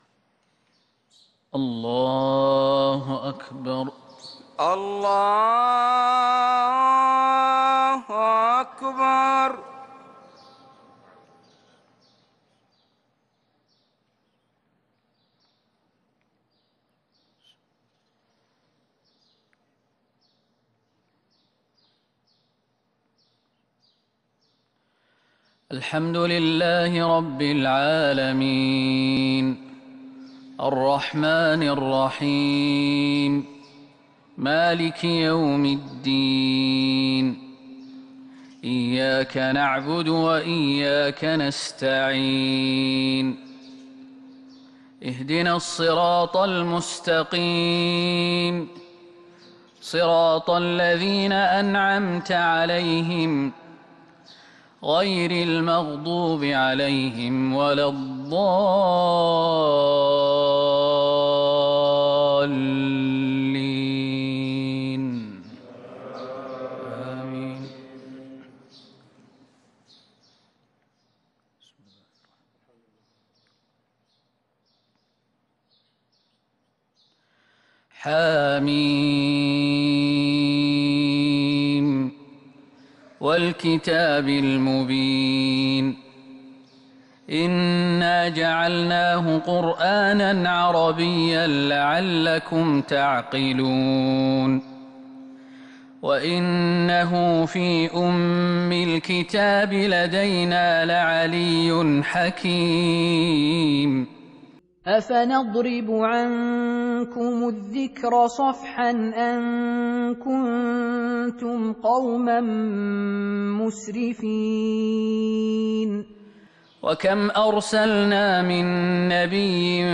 فجر الأثنين 5-6-1442 هــ من سورة الزخرف | Fajr prayer from Surat Az-Zukhruf 18/1/2021 > 1442 🕌 > الفروض - تلاوات الحرمين